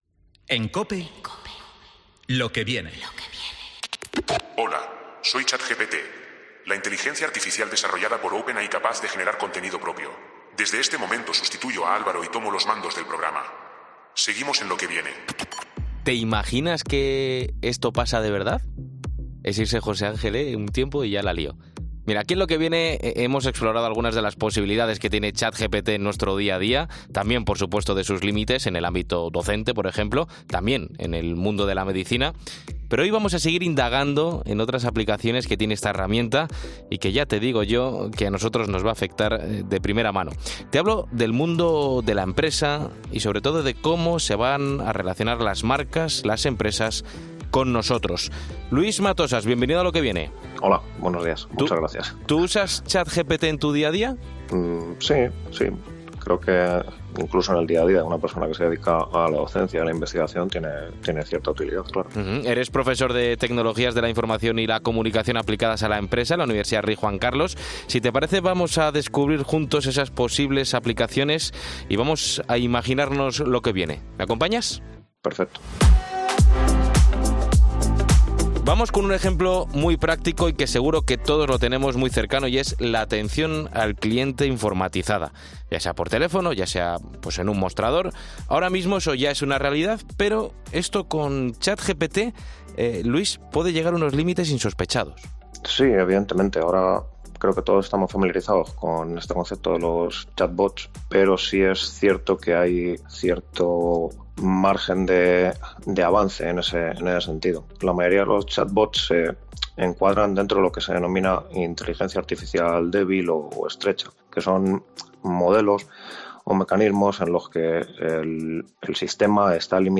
En 'Lo Que Viene' analizamos con un experto en tecnología de la información cómo va a ser la temida llegada de la inteligencia artificial al mundo empresarial